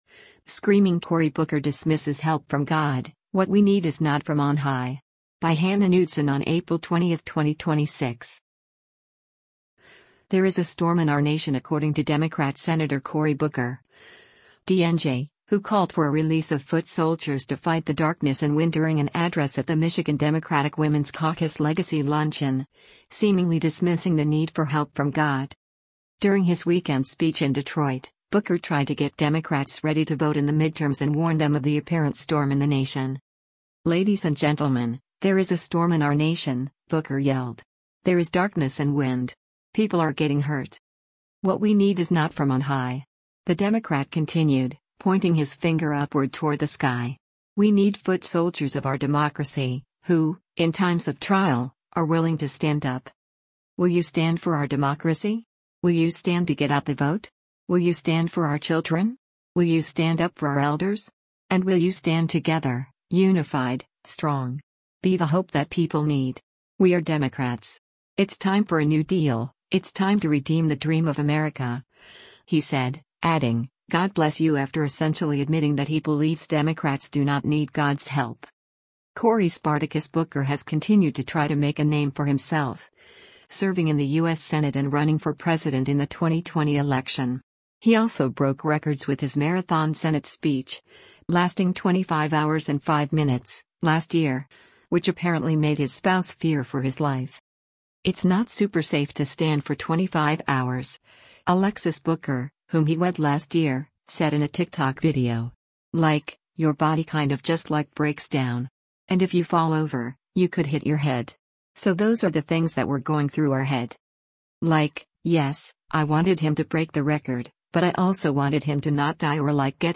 “There is a storm in our nation” according to Democrat Sen. Cory Booker (D-NJ), who called for a release of foot soldiers to fight the “darkness and wind” during an address at the Michigan Democratic Women’s Caucus Legacy Luncheon, seemingly dismissing the need for help from God.
During his weekend speech in Detroit, Booker tried to get Democrats ready to vote in the midterms and warned them of the apparent “storm” in the nation.
“Ladies and gentlemen, there is a storm in our nation!” Booker yelled.